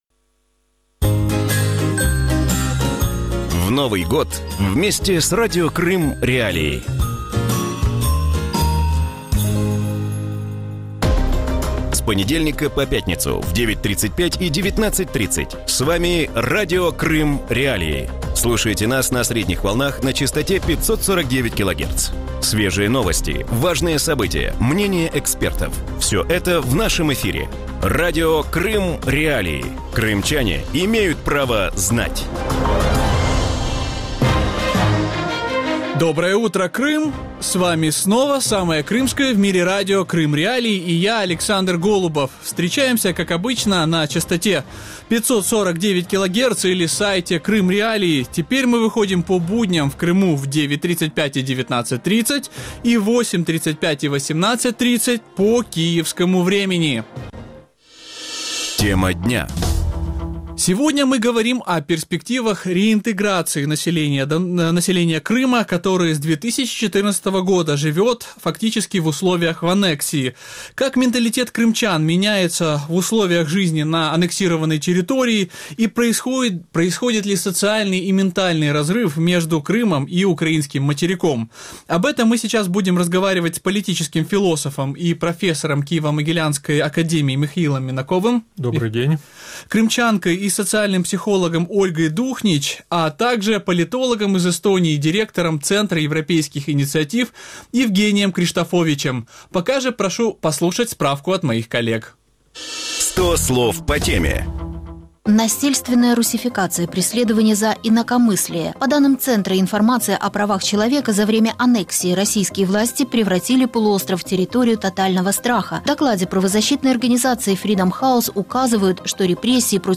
Утренний выпуск новостей о событиях в Крыму. Все самое важное, что случилось к этому часу на полуострове.